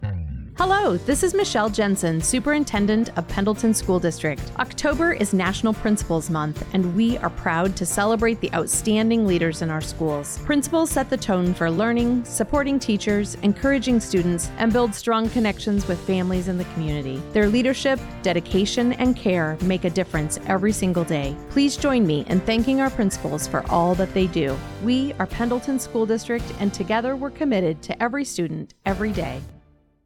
Latest Radio Spot